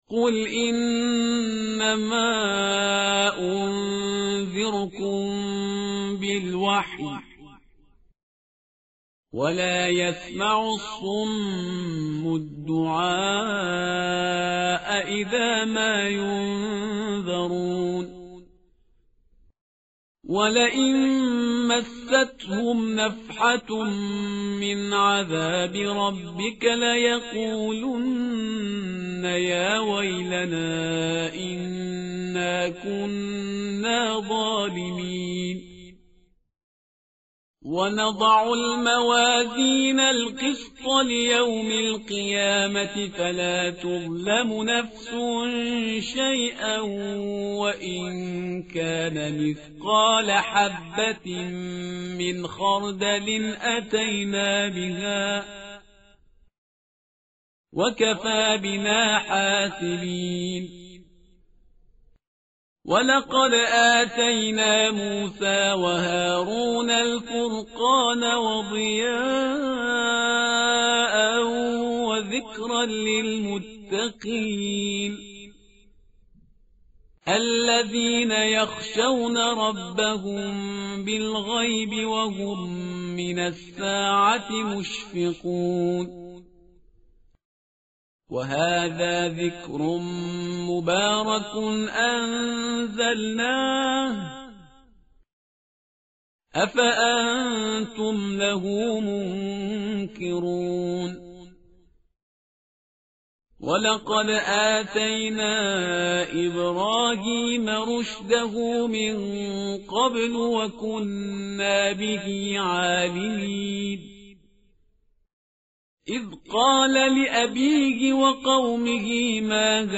متن قرآن همراه باتلاوت قرآن و ترجمه
tartil_parhizgar_page_326.mp3